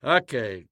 Звуки слова ОК
Окей мужской голос